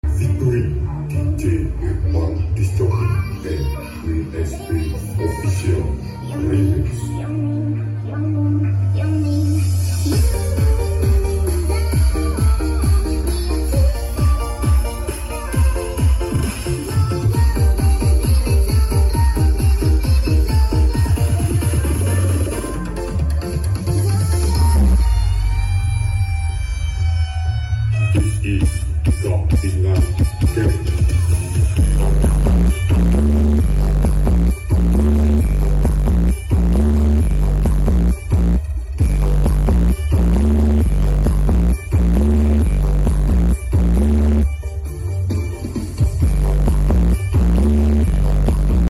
Gladi bersih karnaval peniwen 2025#lewatberanda